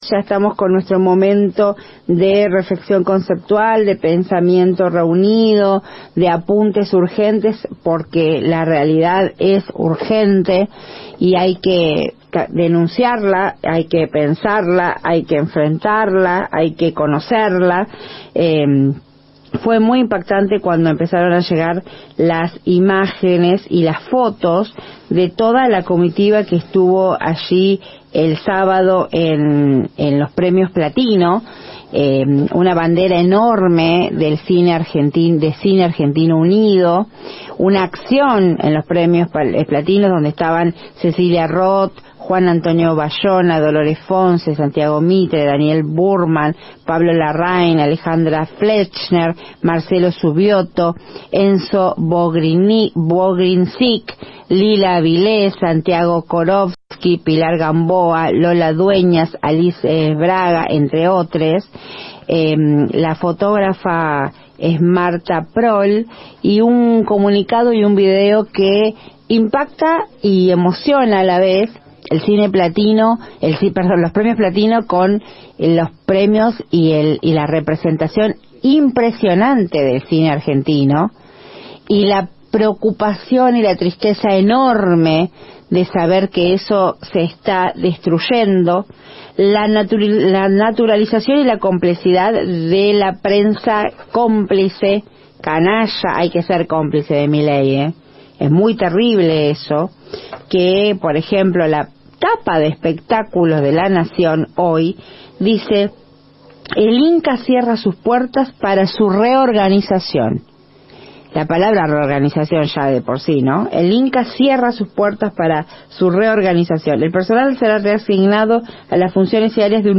En su editorial la periodista y conductora, se refirió a lo ocurrido en Mejico, desde la Rivera Maya en el marco de los Premios Platino.